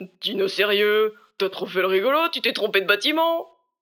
VO_LVL1_EVENT_Mauvais batiment_04.ogg